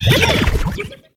Cri de Vorastérie dans Pokémon Soleil et Lune.